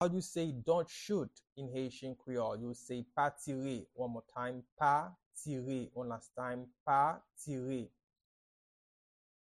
Pronunciation and Transcript:
Dont-shoot-in-Haitian-Creole-–-Pa-tire-pronunciation-by-a-Haitian-teacher.mp3